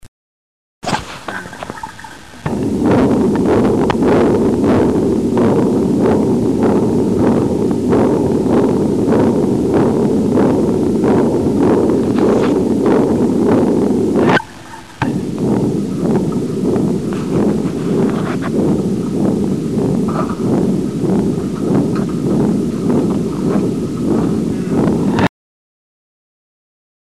Daarmee hoort u de shunt ruisen.
Shuntgeluiden
Normaal OK
Nummer-1-Normaal-OK.mp3